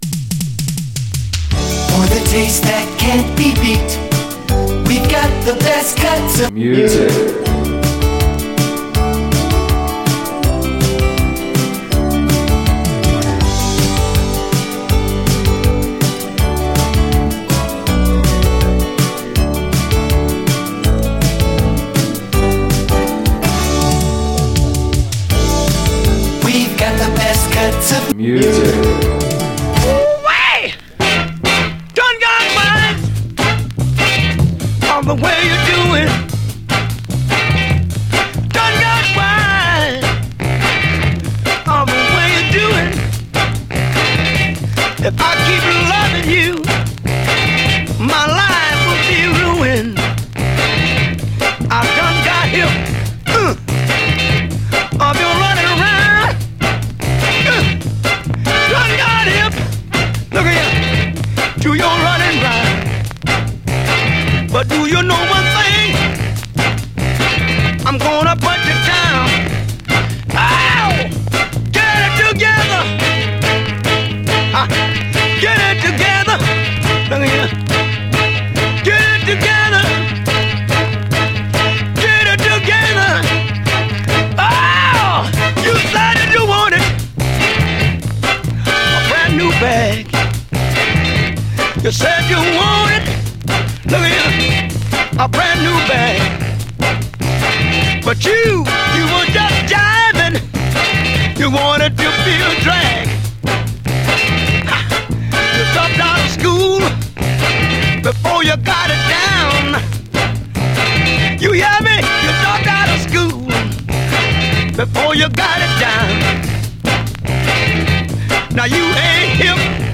Our mixes are guaranteed to rock n’ shake n’ break yo day! download mp3: The Best Cuts of Music: Edition 01 Track list: 1.